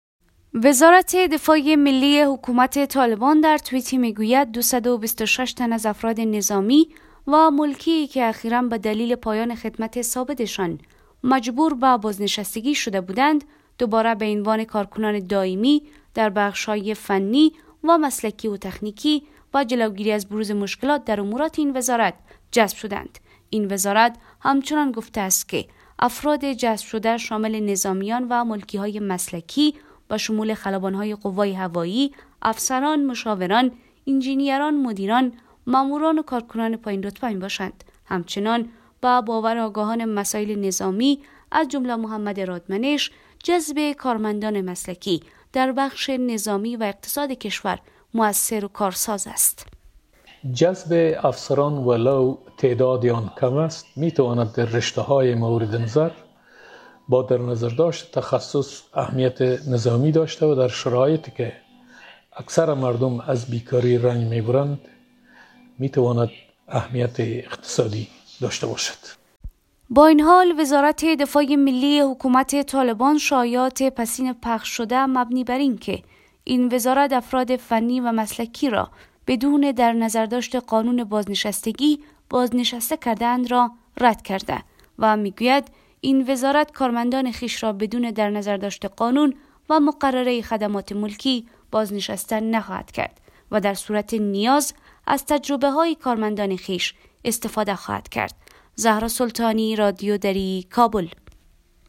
خبر رادیو